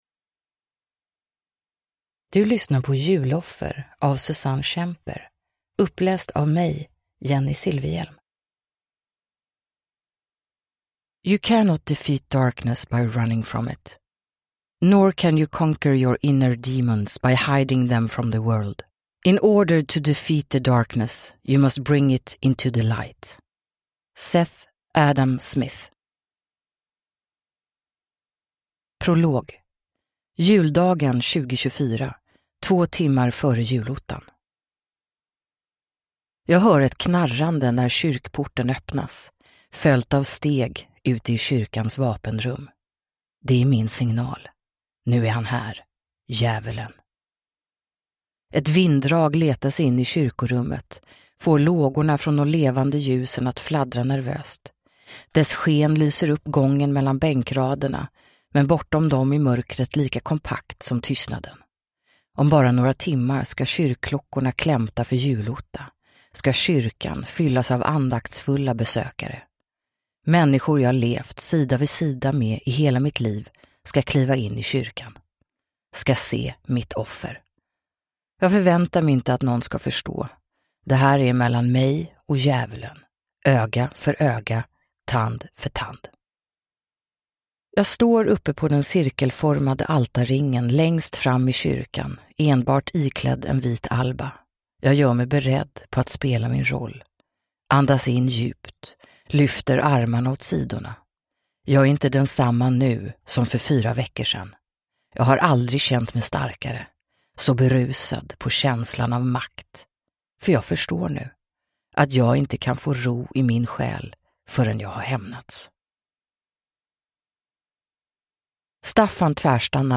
Uppläsare: Jennie Silfverhjelm
Ljudbok